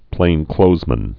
(plānklōzmən, -klōthz-)